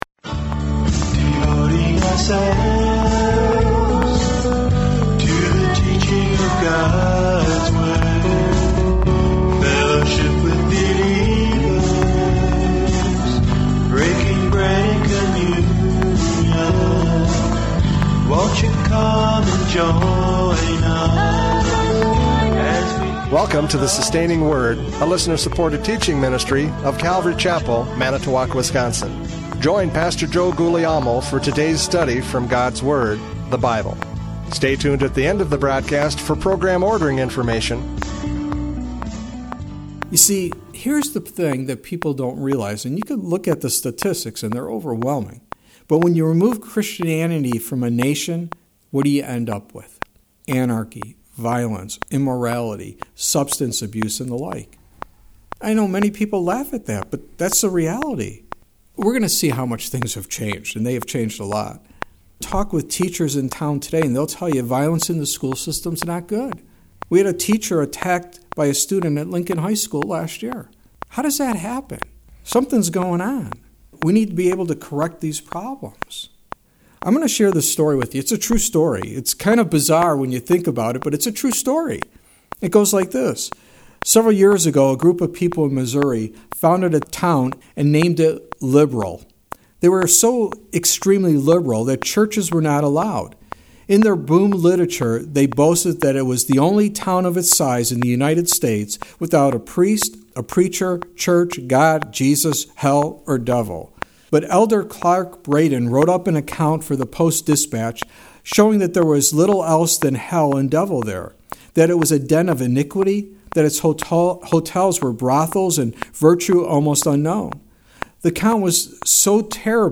John 12:9-11 Service Type: Radio Programs « John 12:9-11 Destroying the Evidence!